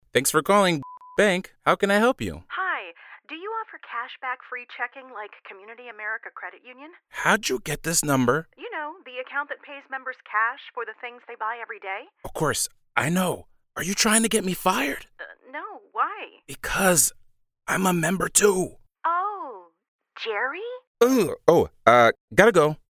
Broadcast Quality Home Studio!
Heart Aware Spot - Somber + Personal + Authentic
Northeastern US, General US